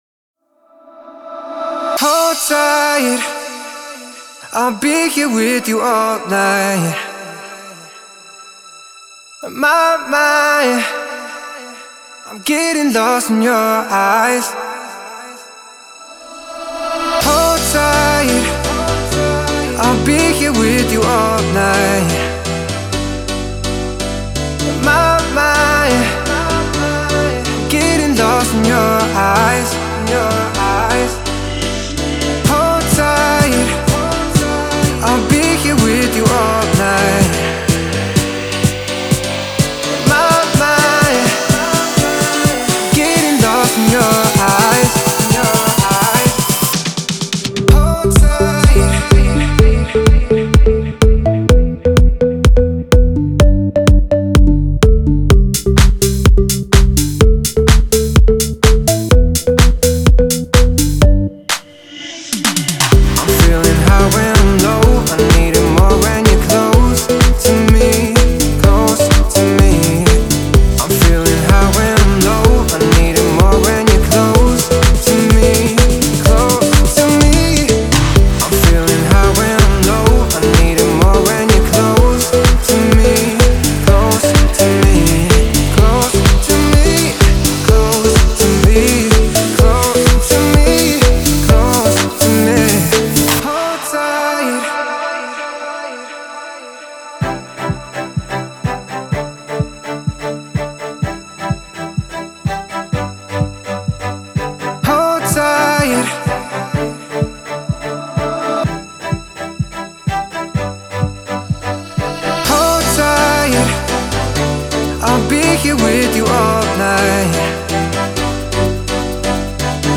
это яркий трек в жанре EDM